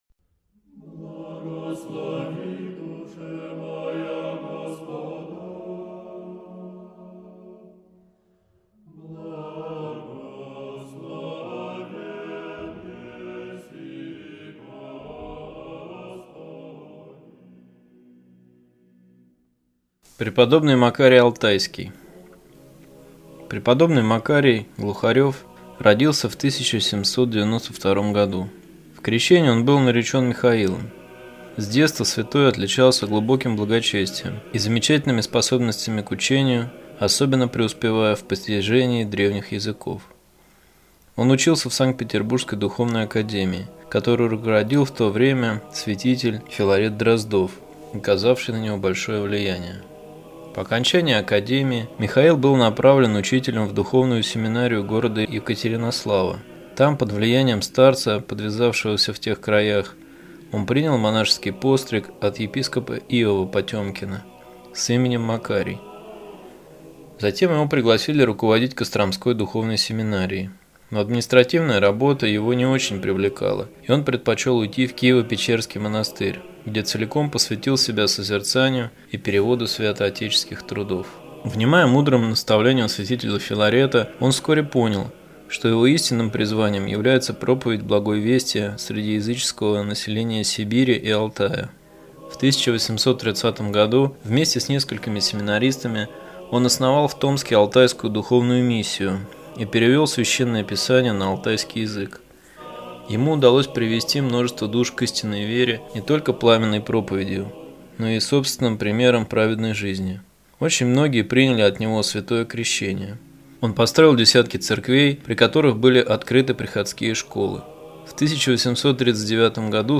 Читает иеромонах